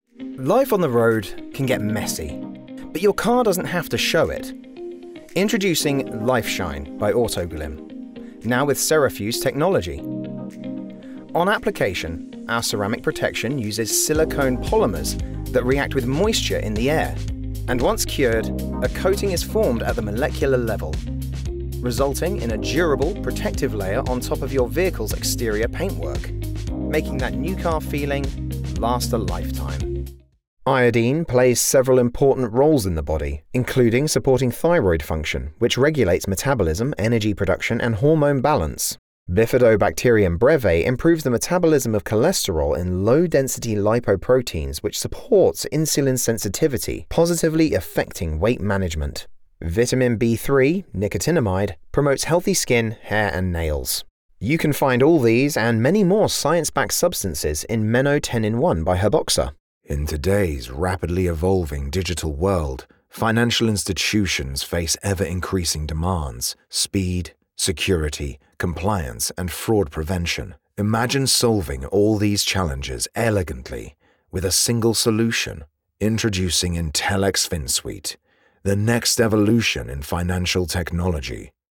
Engels (Brits)
Commercieel, Veelzijdig, Vriendelijk, Natuurlijk, Warm
Explainer